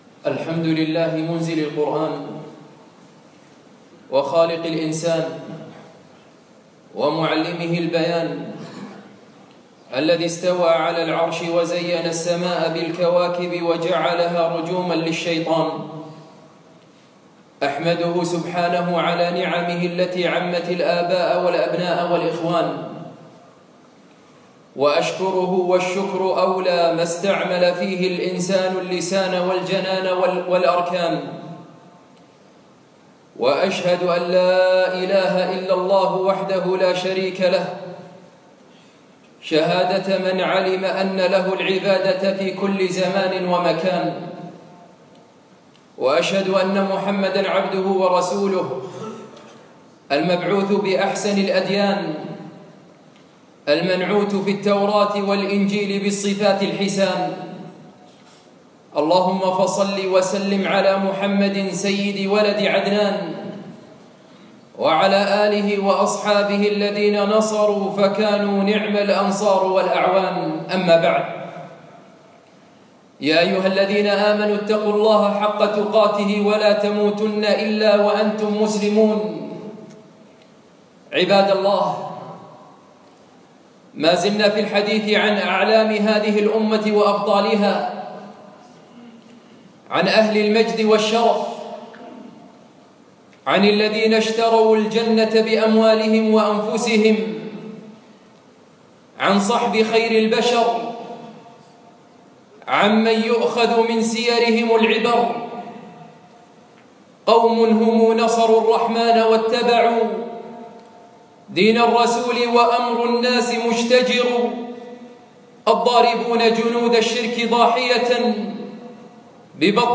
يوم الجمعة 29 رجب 1437هـ الموافق 6 5 2016م في مسجد العلاء بن عقبة الفردوس